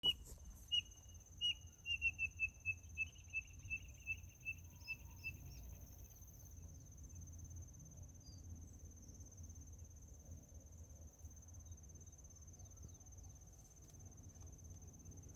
Spotted Nothura (Nothura maculosa)
Province / Department: Entre Ríos
Detailed location: Villa Zorraquin
Condition: Wild
Certainty: Observed, Recorded vocal